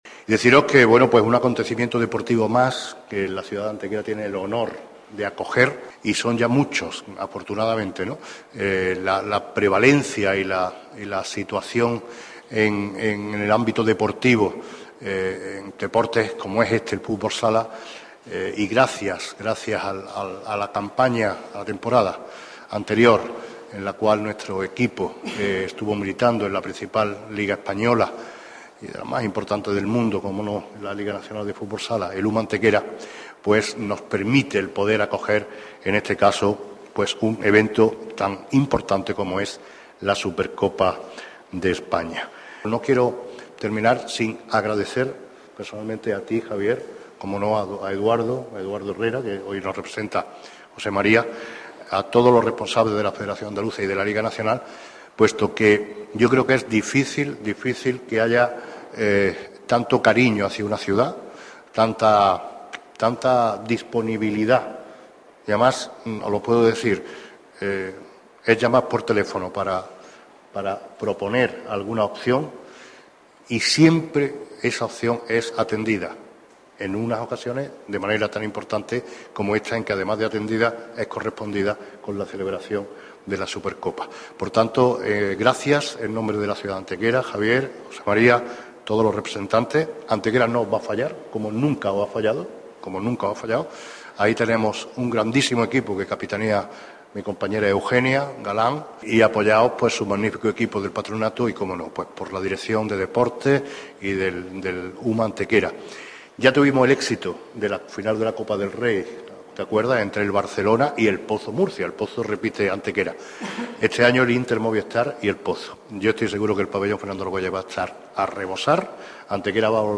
Nota de prensa conjunta del Ayuntamiento de Antequera y la Liga Nacional de Fútbol Sala (LNFS) en referencia al acto de presentación de la Supercopa de España 2016 y el cartel oficial del evento, que ha tenido lugar esta mañana en el Salón de Plenos del Consistorio Antequerano.
Cortes de voz